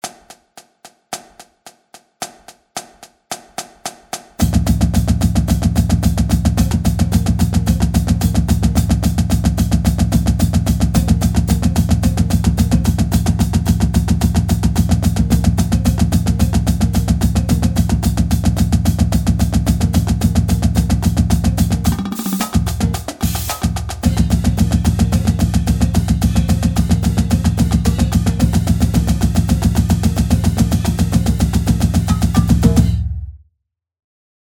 Stick Control 1 - 110.mp3